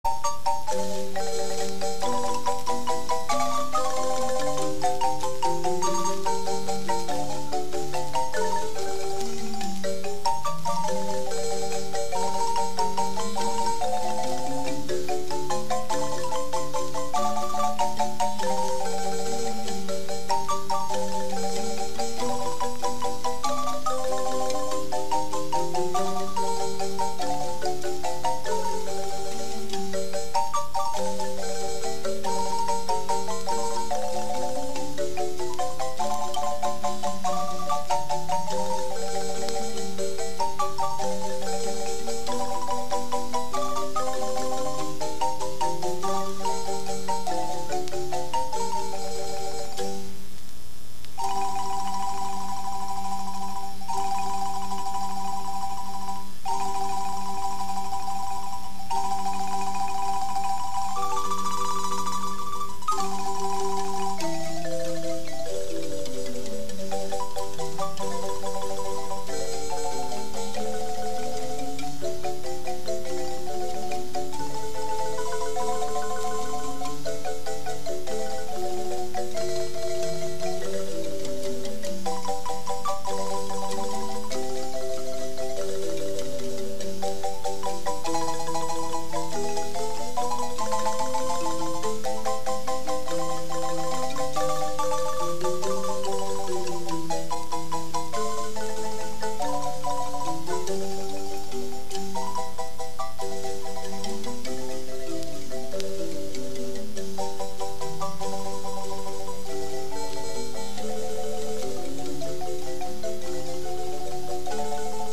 Música guanacasteca: marimba